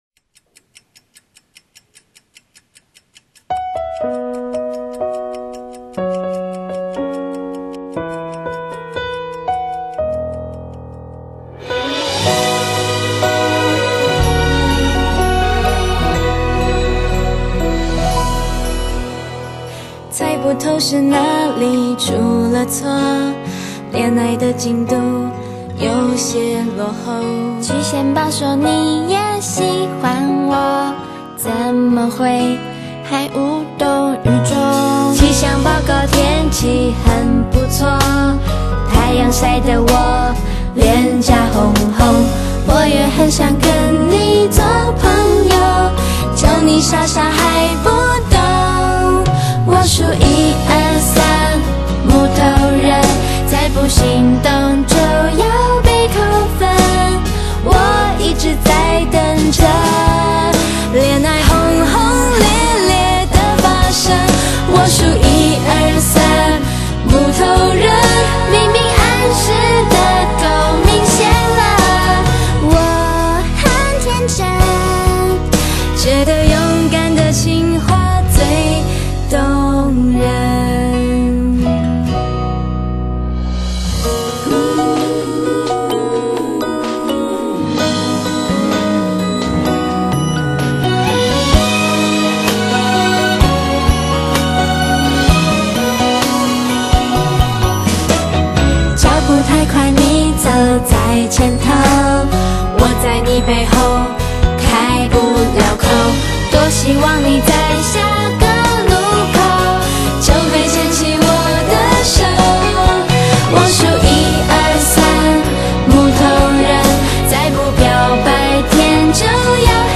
复古华丽摇滚风
外带着节奏明显的啦啦队曲风